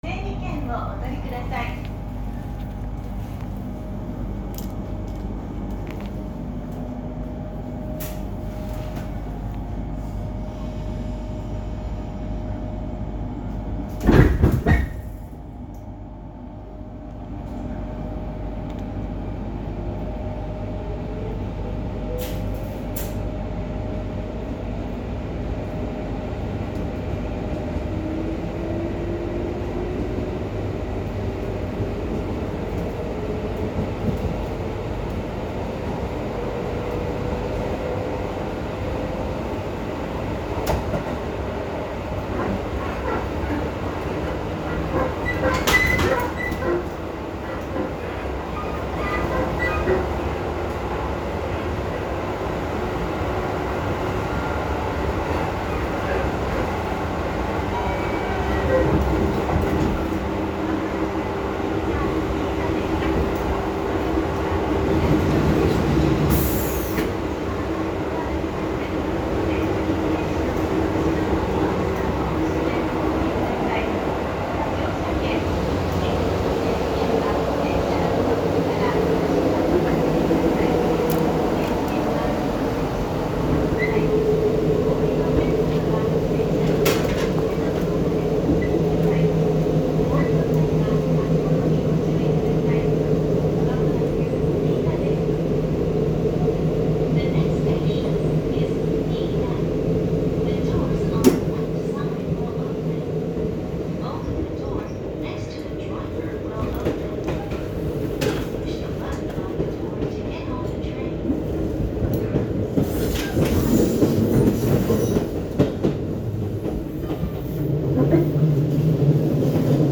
・8100系走行音
【阿武隈急行線】新田→二井田
ごく普通の抵抗制御となります。モーター音はごく普通、揺れや騒音も抵抗制御車としてはよくある物なのですが、近年はこれも貴重になってきているのかもしれません。